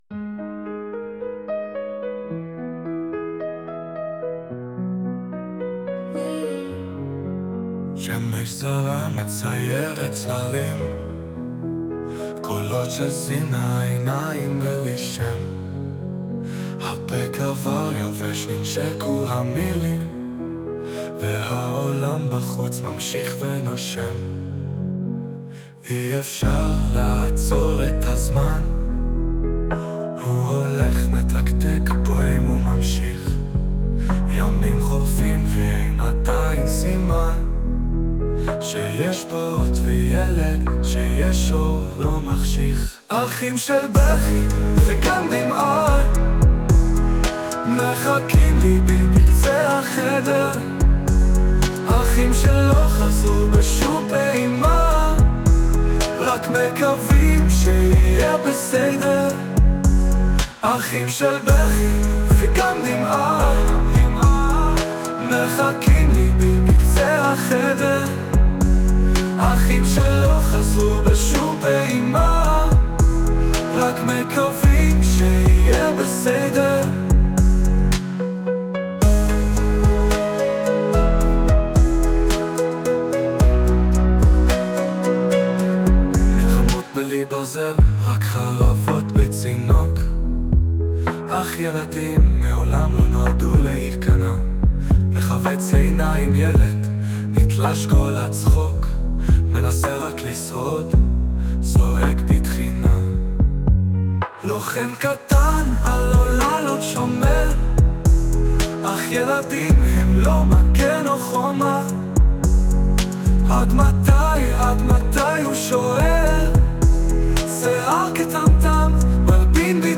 ישנם חסרונות רבים היכולים להיגרם ע"י שירת רובוט.
ולפעמים הלחן מעולה. האווירה תואמת לשיר. העיבוד מצוין! אבל דווקא אז- הוא סובל מבעיות הגייה רבות וקשות.